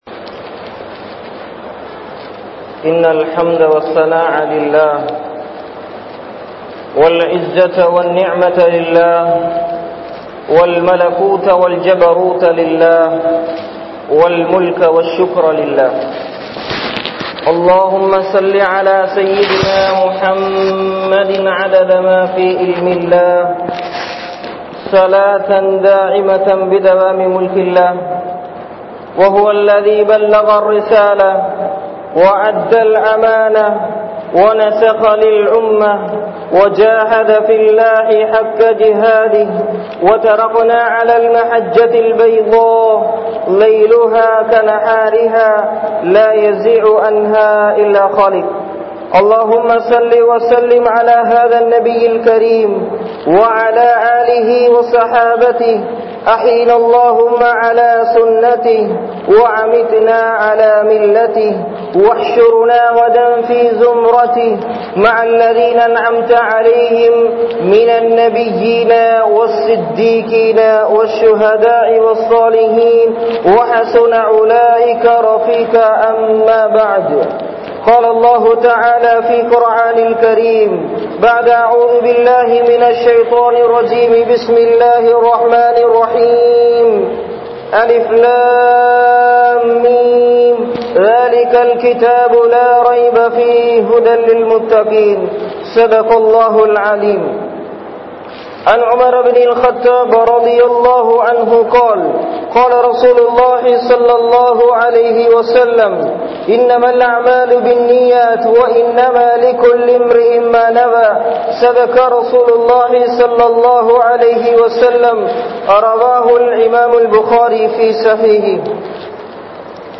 Muslimkalin Naattup Pattru (முஸ்லிம்களின் நாட்டுப்பற்று) | Audio Bayans | All Ceylon Muslim Youth Community | Addalaichenai
Welipanna Town Jumua Masjidh